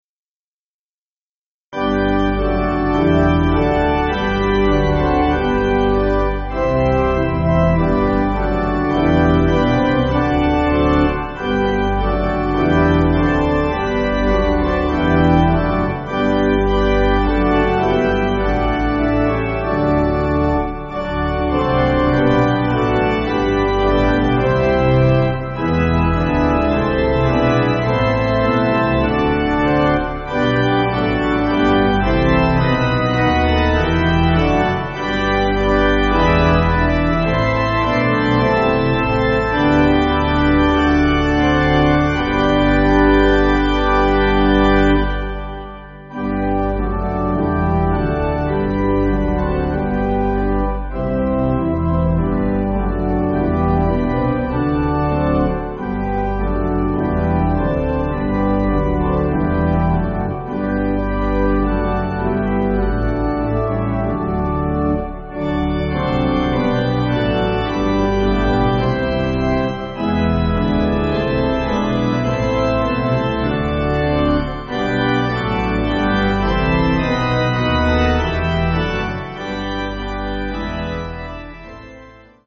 Organ
(CM)   5/G